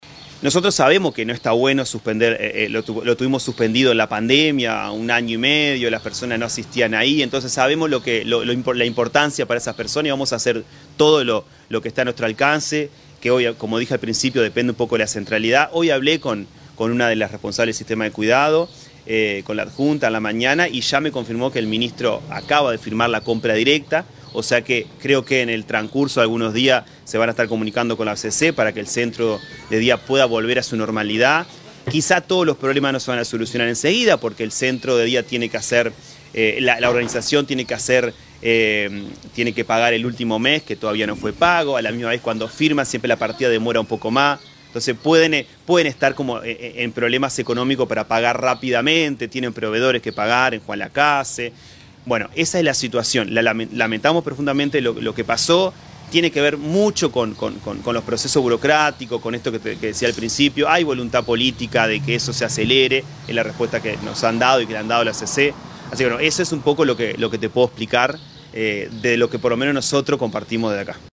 Al respecto, escuchamos a Maximiliano Olaverry, director departamental del Mides.